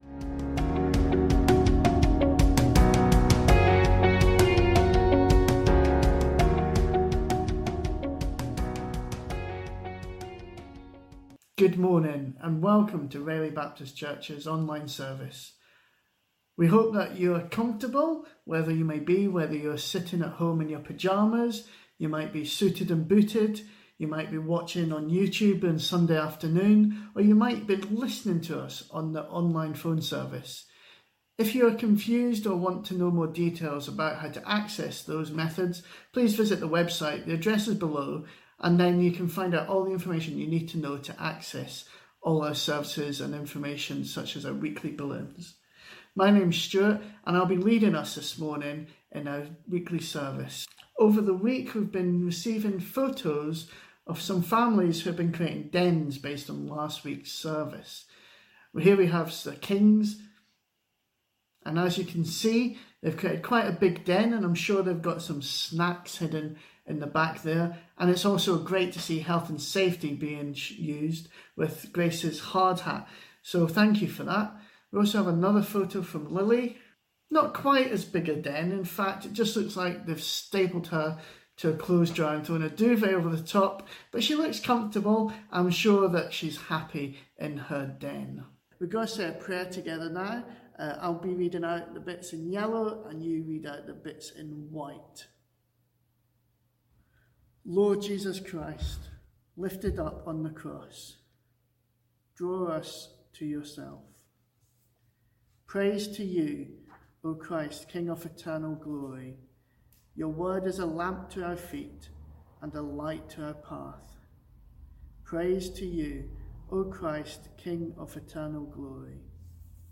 A message from the series "Faith for Lifes Journey."